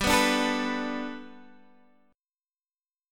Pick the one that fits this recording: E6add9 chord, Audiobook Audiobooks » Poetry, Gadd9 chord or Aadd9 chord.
Gadd9 chord